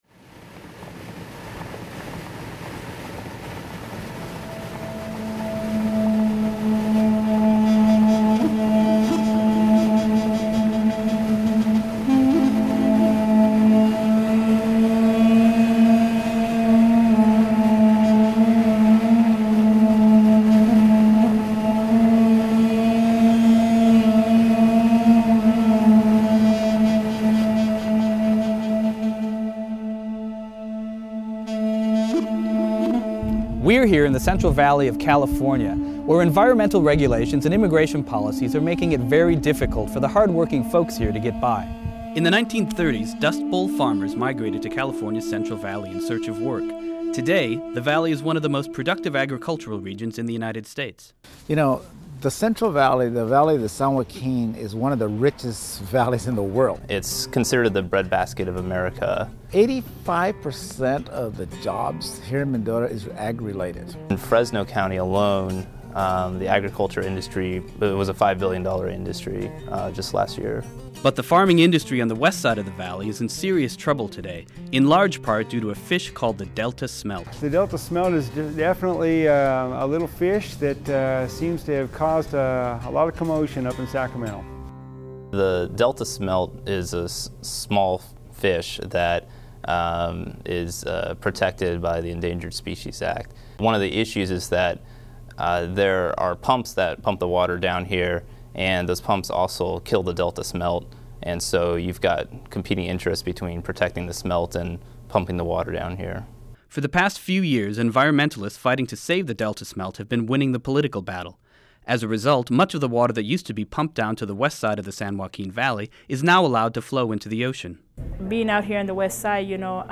To learn more about the Central Valley and its discontents, we spoke to Robert Silva, mayor of Mendota;